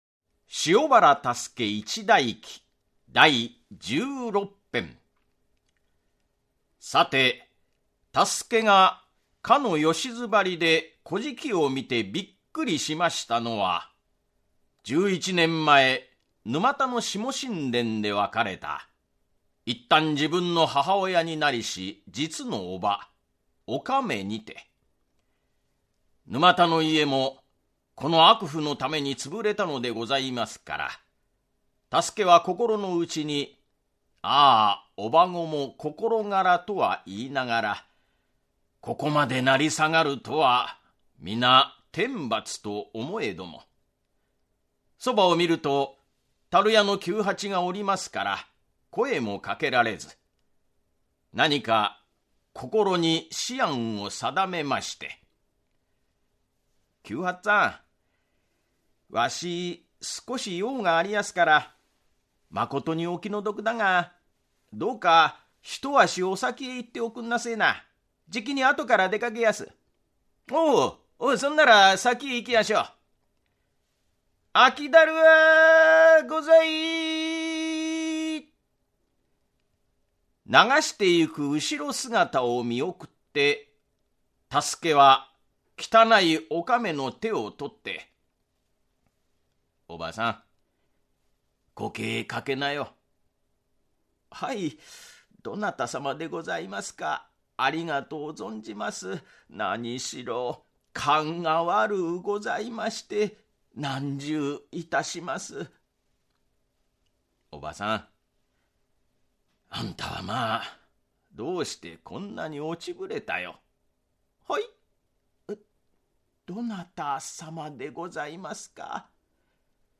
[オーディオブック] 塩原多助一代記-第十六・十七・十八編-